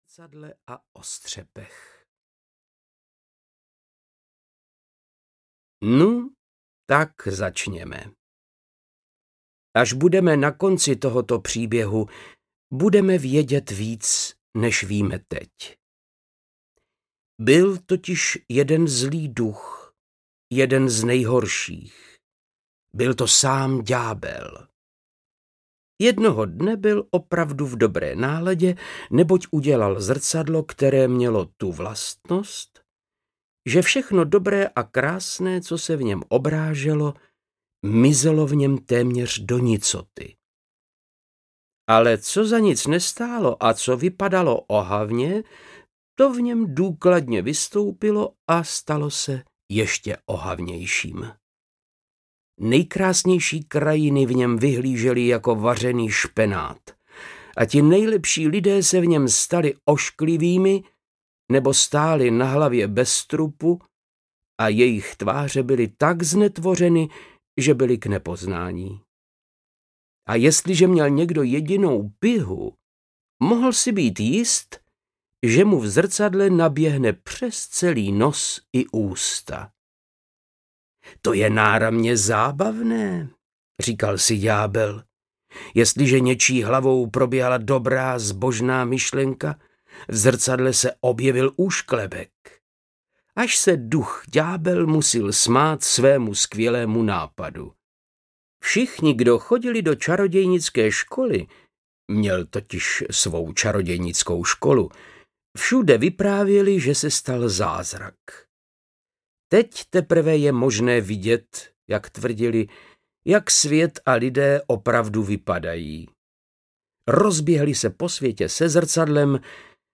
Sněhová královna audiokniha
Ukázka z knihy
• InterpretVáclav Knop
snehova-kralovna-audiokniha